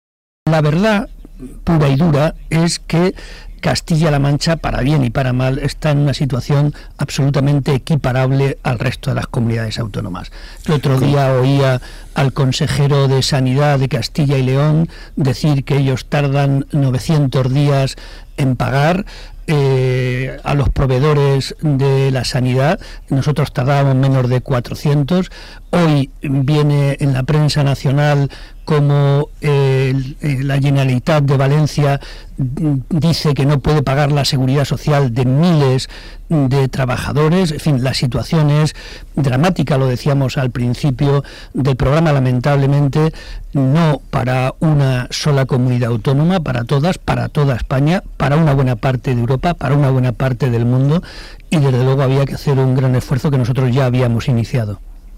El Presidente del Grupo Parlamentario y secretario general de los socialistas de Castilla-La Mancha, José María Barreda, ha realizado una entrevista en el programa radiofónico “Herrera en la Onda”, de Onda Cero.